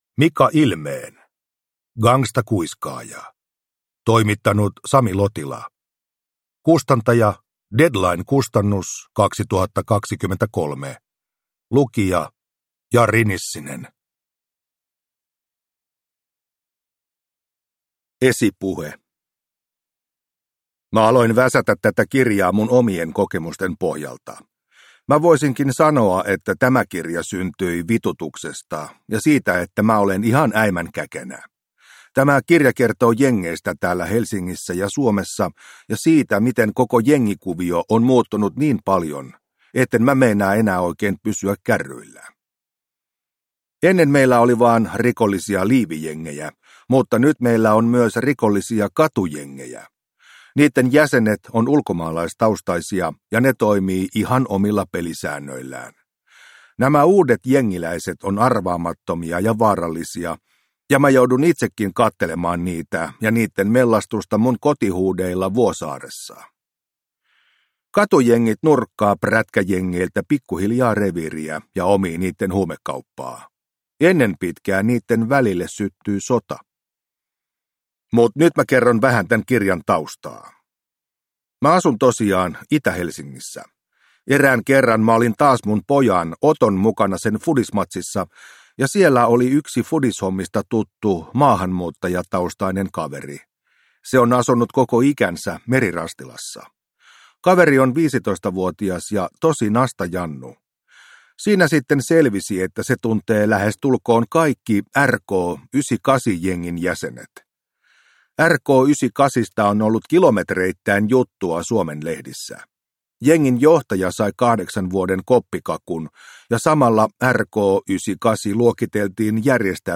Immu Gangstakuiskaaja – Ljudbok – Laddas ner